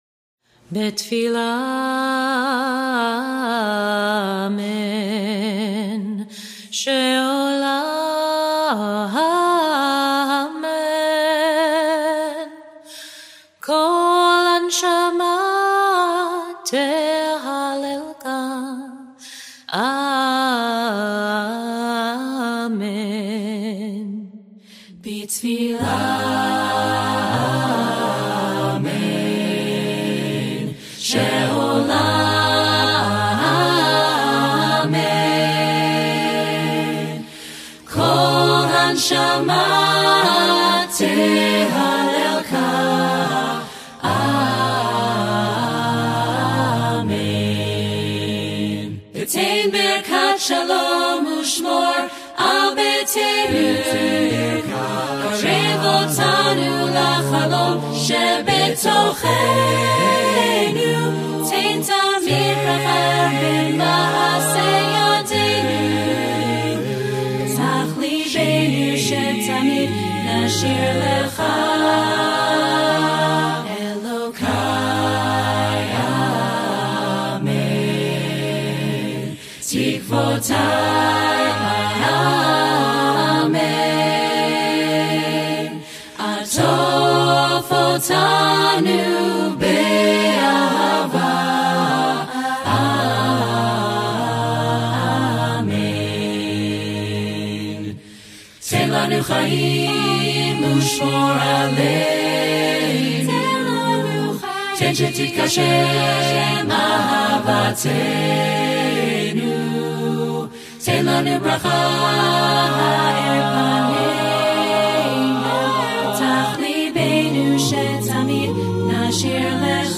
Genre: Israeli
Contains solos: Yes